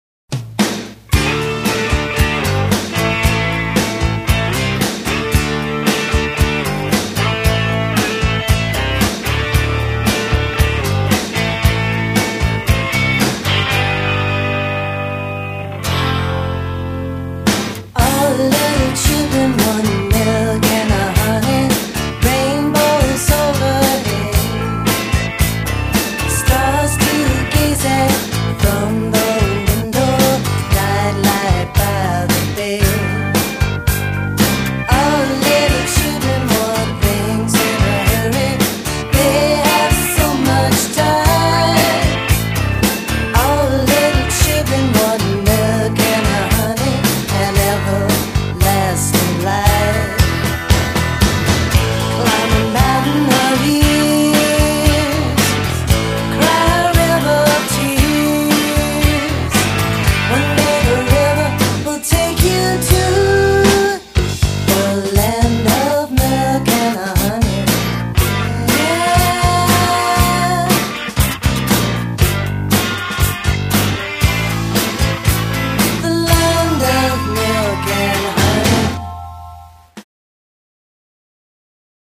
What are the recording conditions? recorded in Austin and Houston,TX